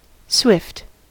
swift: Wikimedia Commons US English Pronunciations
En-us-swift.WAV